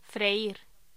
Locución: Freír